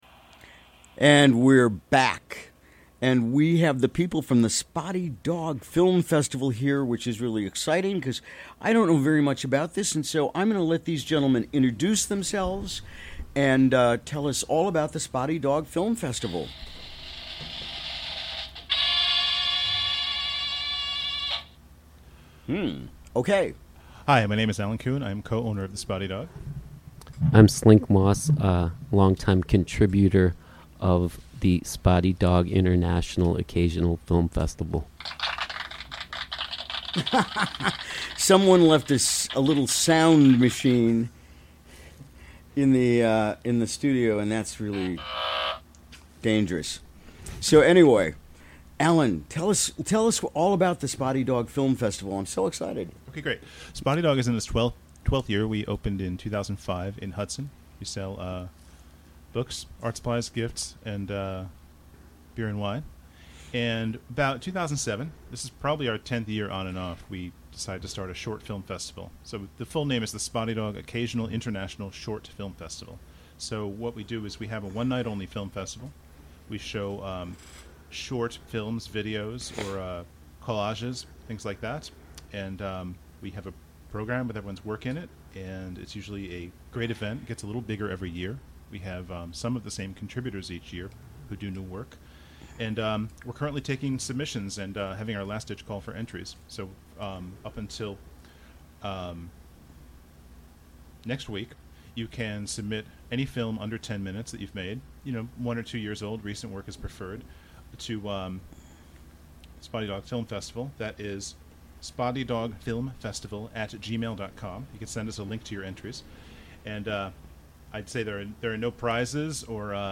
Recorded during the WGXC Morning Show on September 27, 2017.